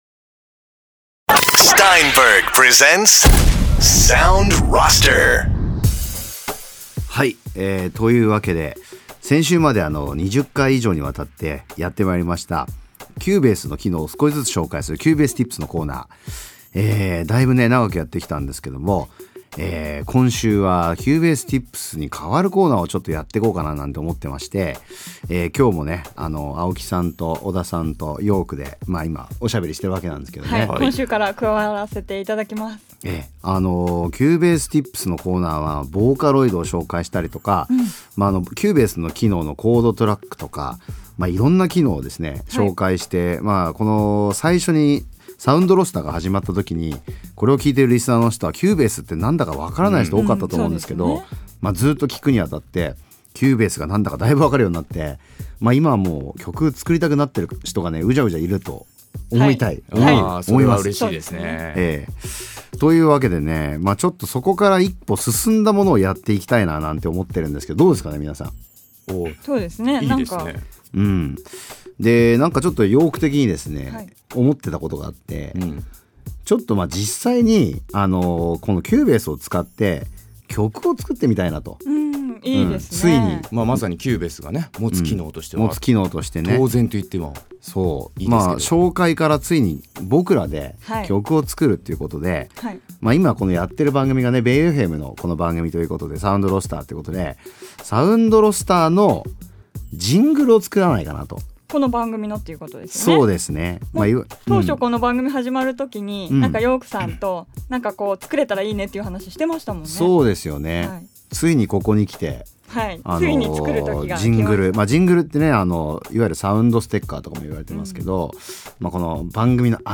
Steinberg が提供するラジオ番組「Sound Roster」。